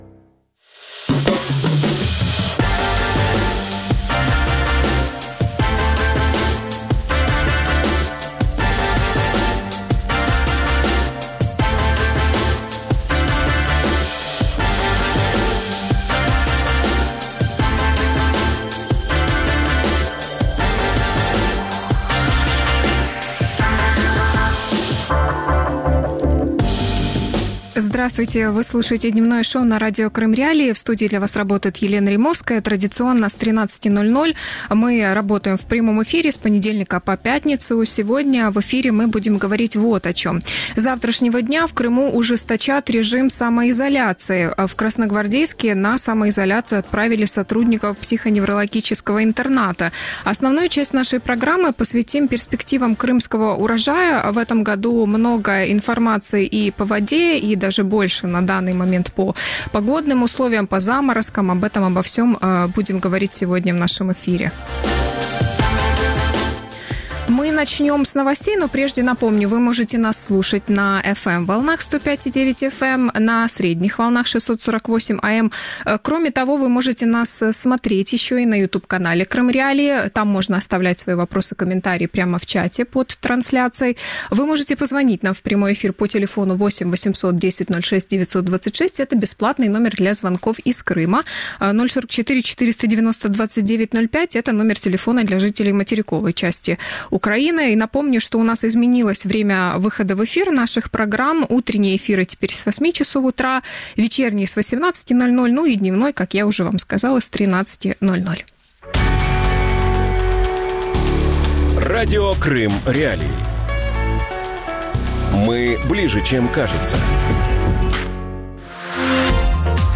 Урожай и цены в Крыму | Дневное ток-шоу